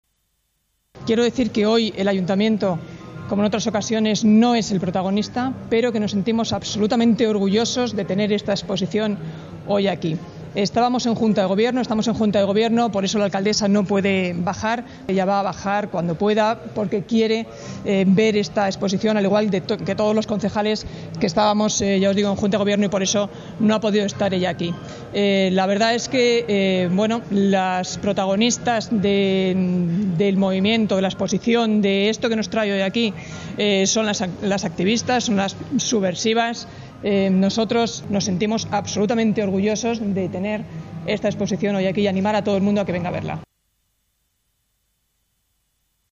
La delegada de Equidad, Derechos Sociales y Empleo, Marta Higueras, ha asistido este mediodía a la inauguración de la exposición